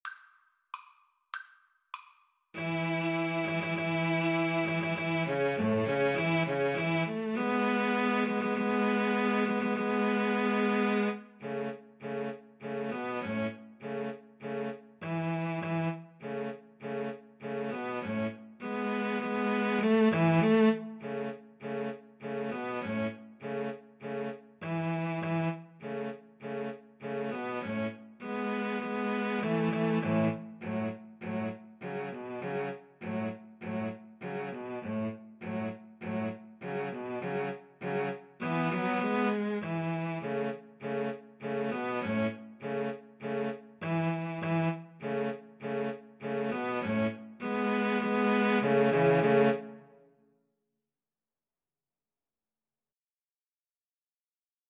Play (or use space bar on your keyboard) Pause Music Playalong - Player 1 Accompaniment Playalong - Player 3 Accompaniment reset tempo print settings full screen
A major (Sounding Pitch) (View more A major Music for Cello Trio )
Presto =200 (View more music marked Presto)